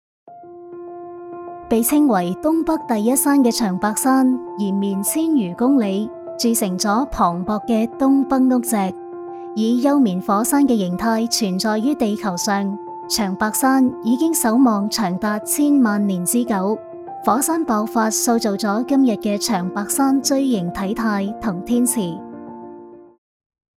粤语，英文女78 | 聆听我的声音
【女78号粤语解说】吉林长白山.mp3